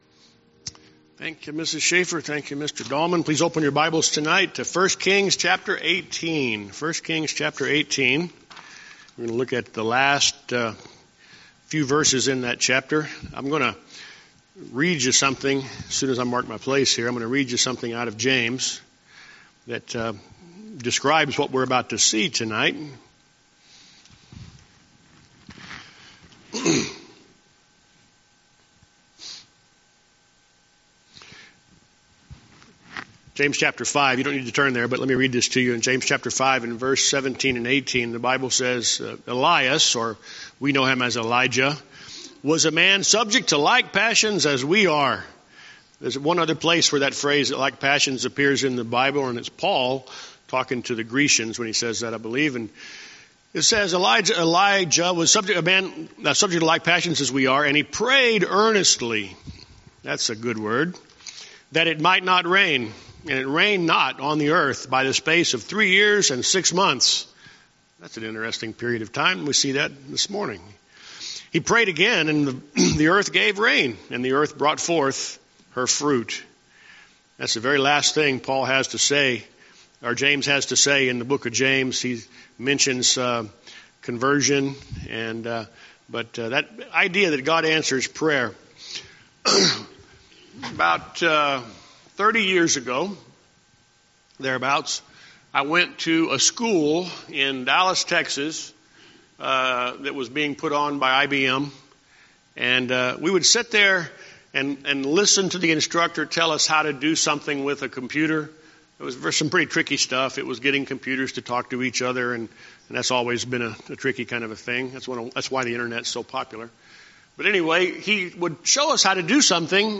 Sermons And Lessons